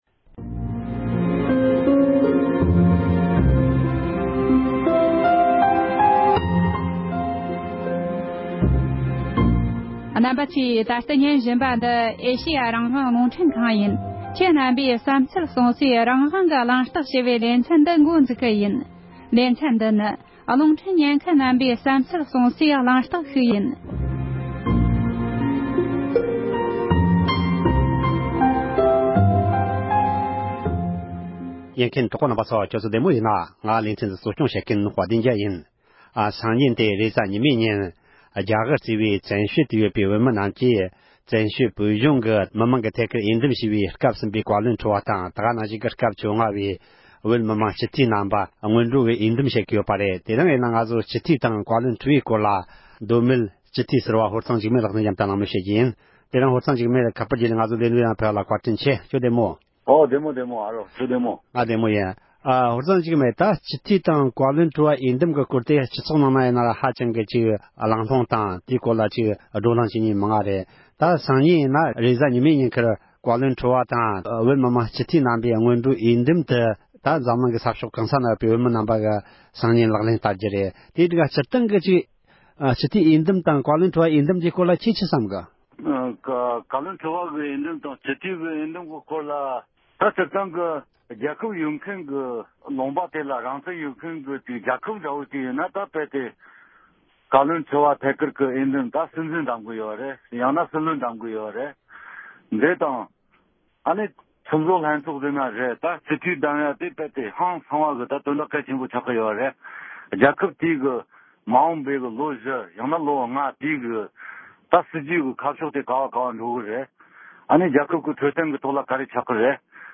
མདོ་སྨད་སྤྱི་འཐུས་ཟུར་བ་ཧོར་གཙང་འཇིགས་མེད་ལགས་དང་མཉམ་དུ་སྤྱི་འཐུས་ཀྱི་ལས་འགན་སྐོར་གླེང་མོལ་ཞུས་པ།